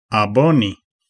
Ääntäminen
IPA : /səbˈskraɪb/